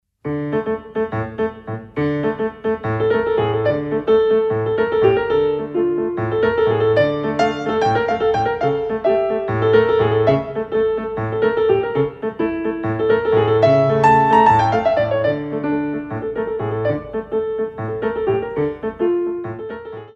Medium allegro 1
6/8 - 12x8